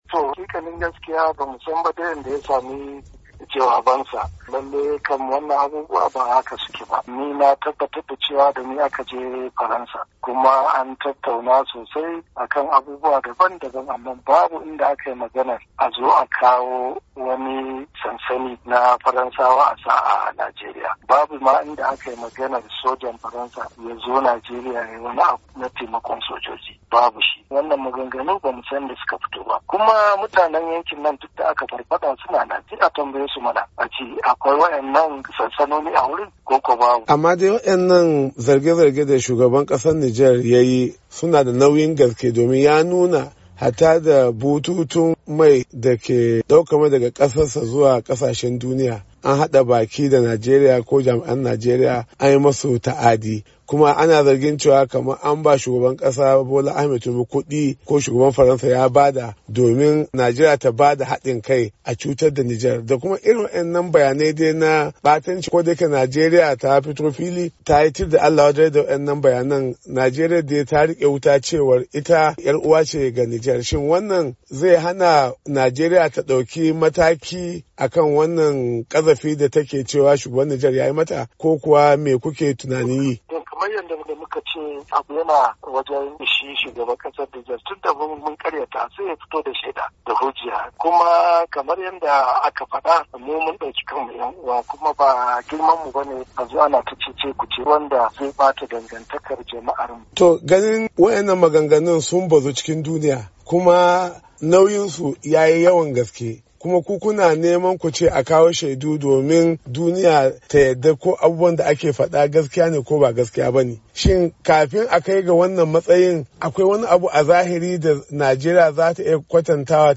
Hirar Babban Ministan Tsaro Kan Zargin Ta'addancin Da Nijar Ke Yi Wa Najeriya